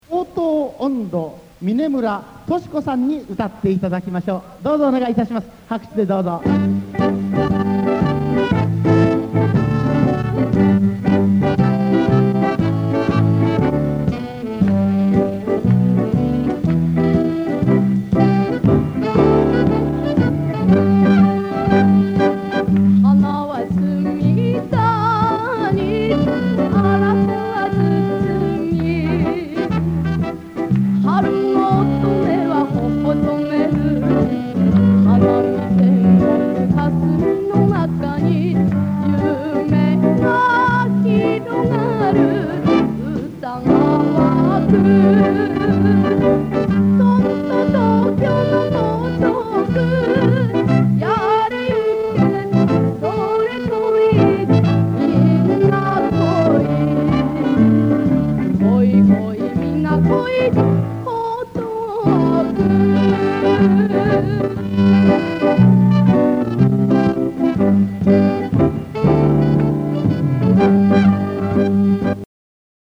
発表授与と春日八郎ショー（江東区文化センターのライブより）